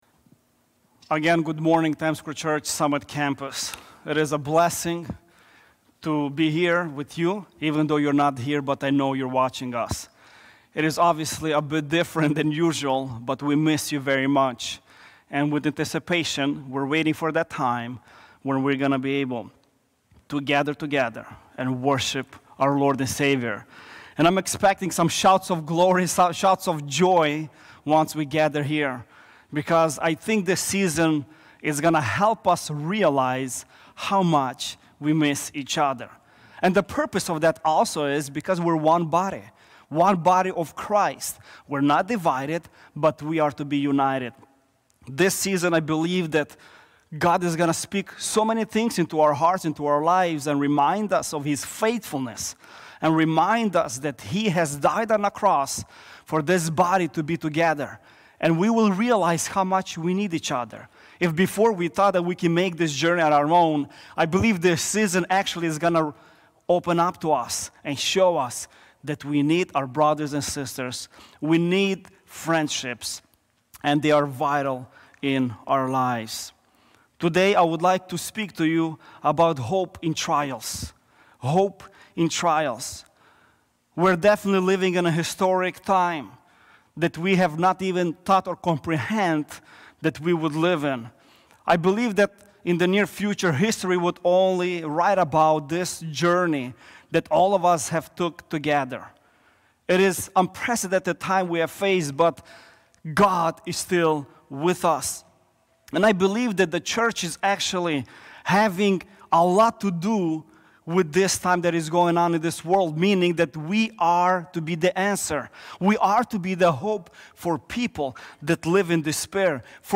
Hope In Trials | Times Square Church Sermons